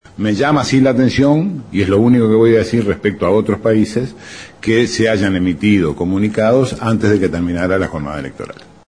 Una vez finalizado un nuevo Consejo de Ministros en Torre Ejecutiva, el ministro de Relaciones Exteriores, Rodolfo Nin Novoa, brindó una conferencia de prensa refiriéndose a la posición de Uruguay frente a la situación política de Venezuela.